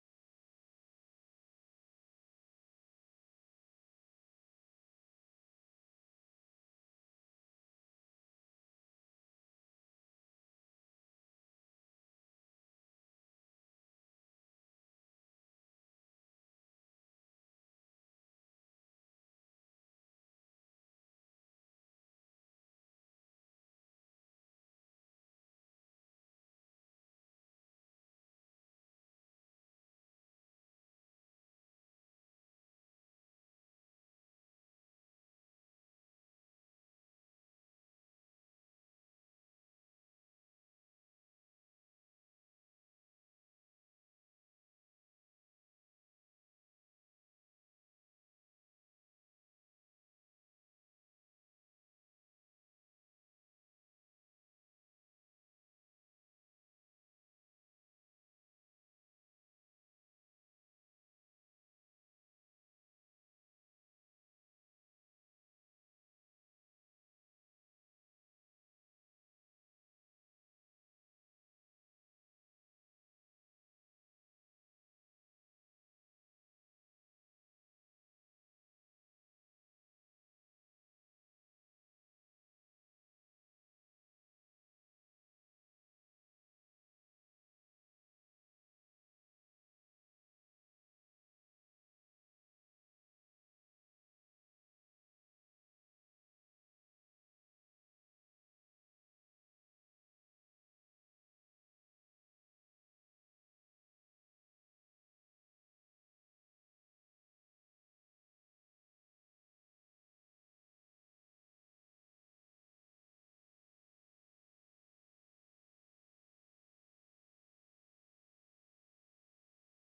마태복음 24장 1-4절 [미혹을 받지 않도록 주의하라] - 주일/수요예배 설교 - 주 예수 그리스도 이름 예배당